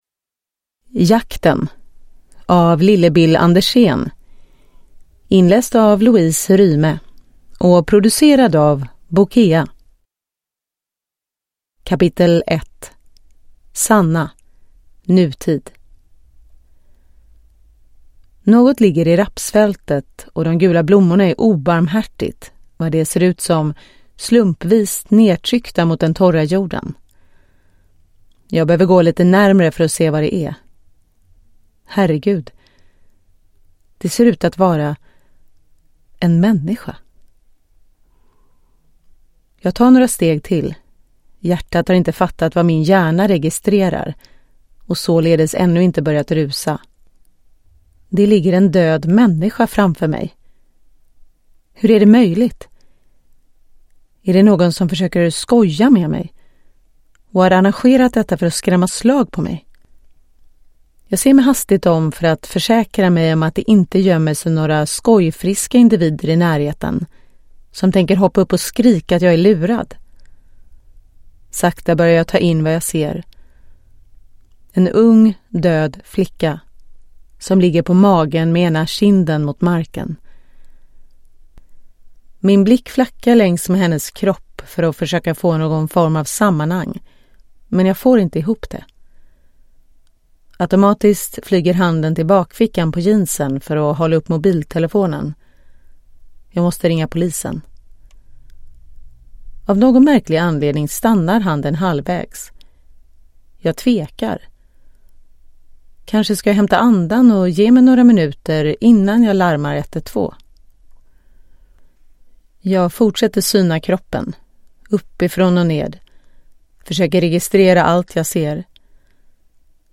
Jakten (ljudbok) av Lillebil Andersen